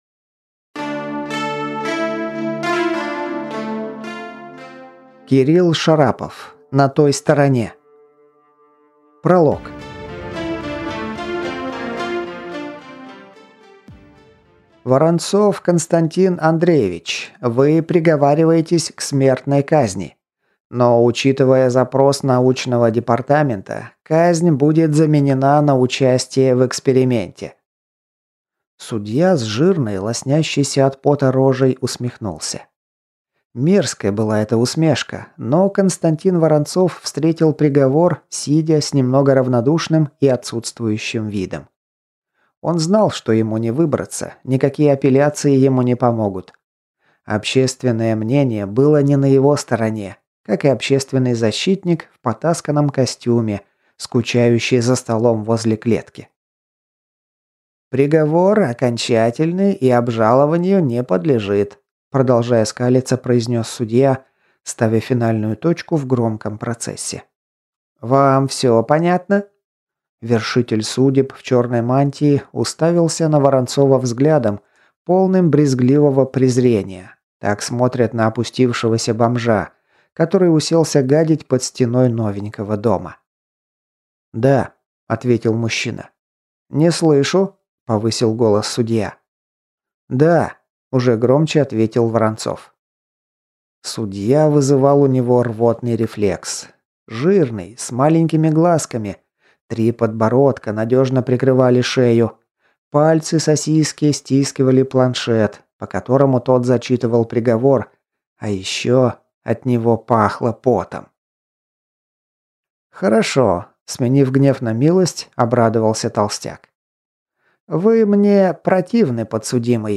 Аудиокнига На той стороне. Книга 1 | Библиотека аудиокниг